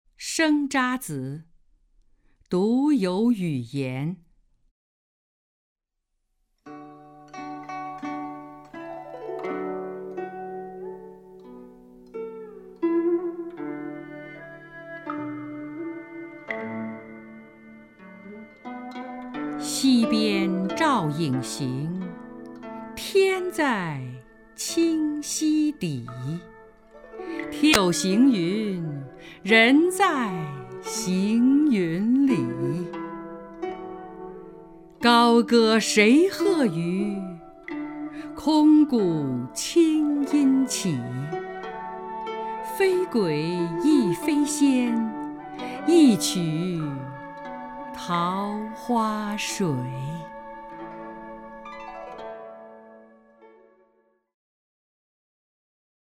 首页 视听 名家朗诵欣赏 张筠英
张筠英朗诵：《生查子·独游雨岩》(（南宋）辛弃疾)　/ （南宋）辛弃疾